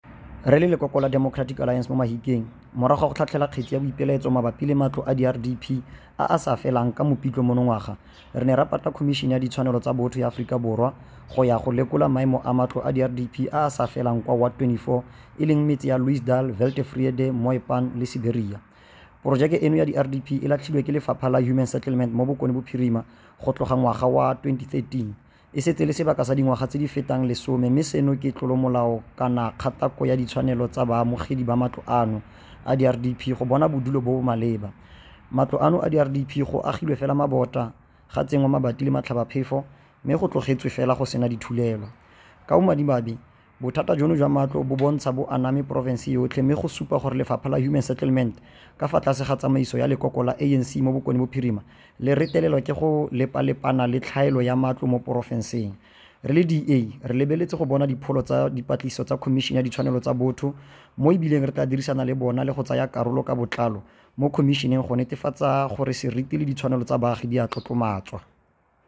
Note to Broadcasters: Please find linked soundbites in
Setswana by Cllr Neo Mabote.